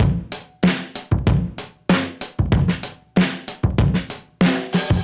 Index of /musicradar/dusty-funk-samples/Beats/95bpm/Alt Sound